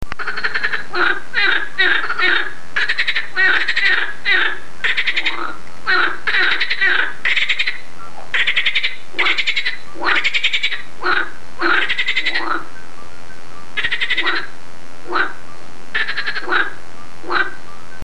La grenouille rieuse et l'Alytes:
pelophylax.mp3